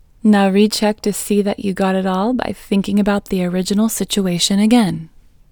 IN – the Second Way – English Female 25